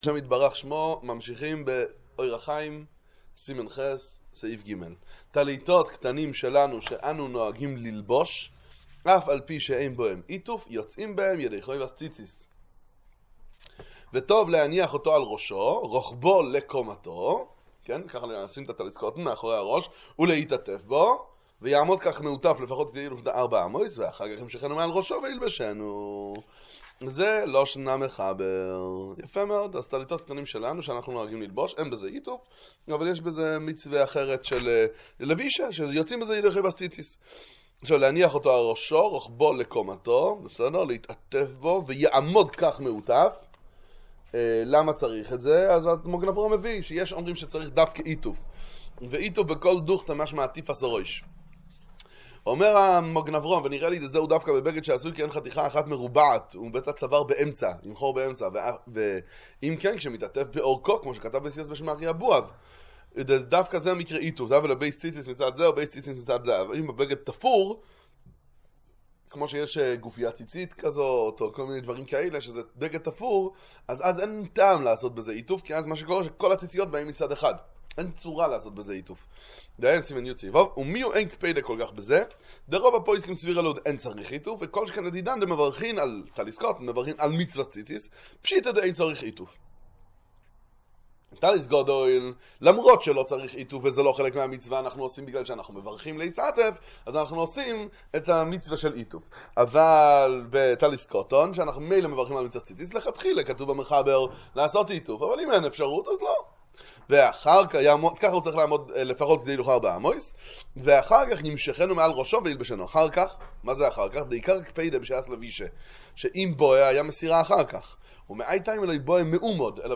שיעור בשולחן ערוך עם ביאור הגר"א הלכות ציצית